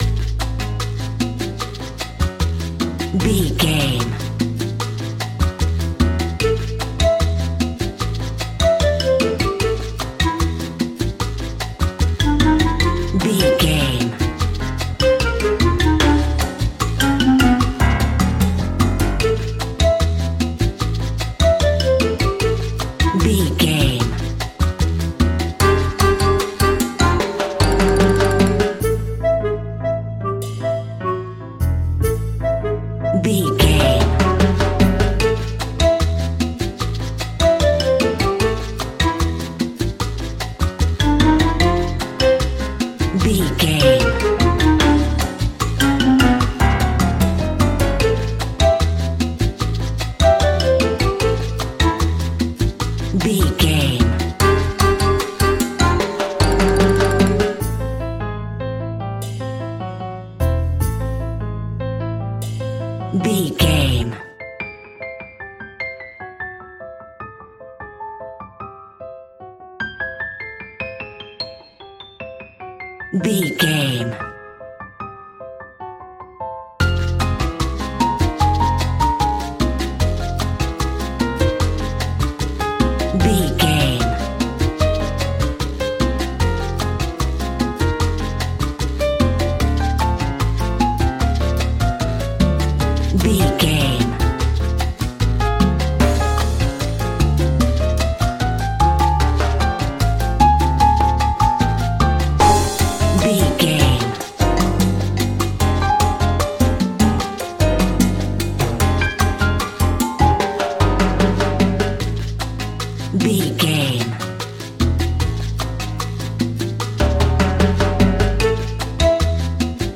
Uplifting
Aeolian/Minor
percussion
flutes
piano
drums
orchestra
double bass
silly
circus
goofy
perky
Light hearted
quirky